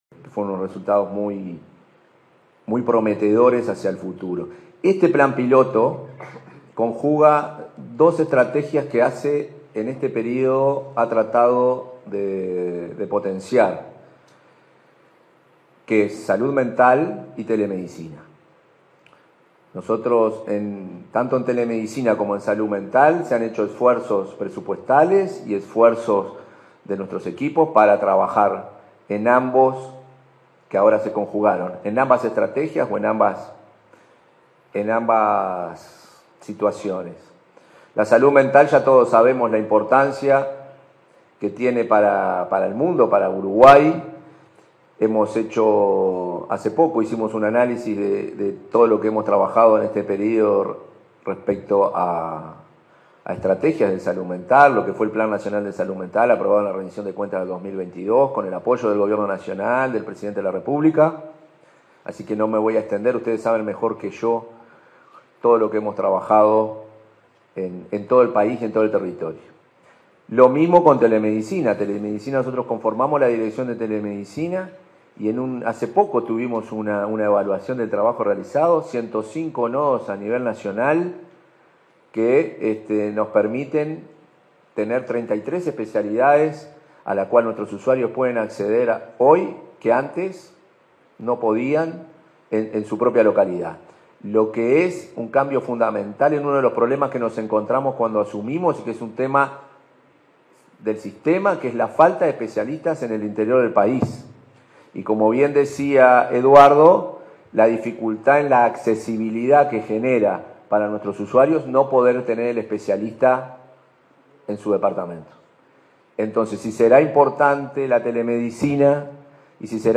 Palabras del presidente de ASSE, Marcelo Sosa
Palabras del presidente de ASSE, Marcelo Sosa 09/12/2024 Compartir Facebook X Copiar enlace WhatsApp LinkedIn En el marco de la presentación de los resultados de un proyecto de teleasistencia en salud mental, este 9 de diciembre, se expresó el presidente de la Administración de los Servicios de Salud del Estado, Marcelo Sosa.